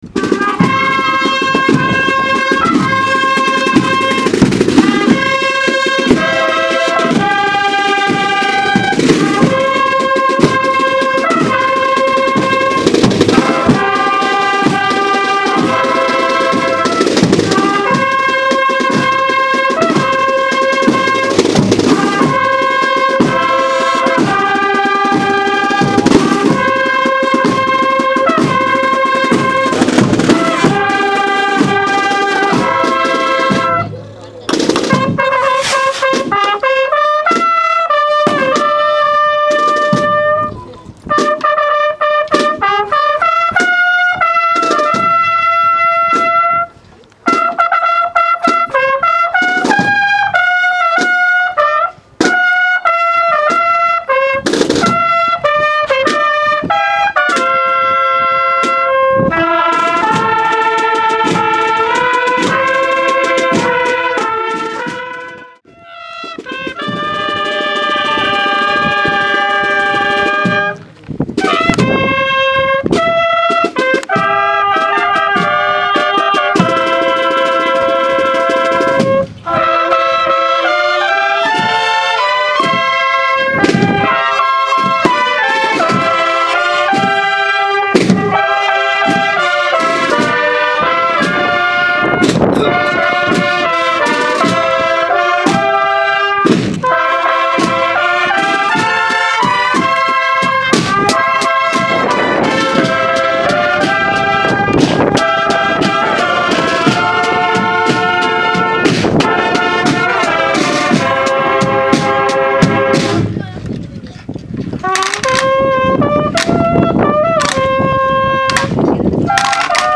Procesión Raiguero Bajo 2014
El pasado domingo 27 de julio tuvo lugar en la pedanía del Raiguero Bajo una Solemne Procesión con las imágenes de Santiago Apóstol, San Fulgencio y La Purísima, que contó con la asistencia de vecinos, autoridades municipales y eclesiásticas y la banda de cornetas y tambores de la Hermandad de Jesús en el Calvario y Santa Cena.